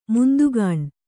♪ mundugāṇ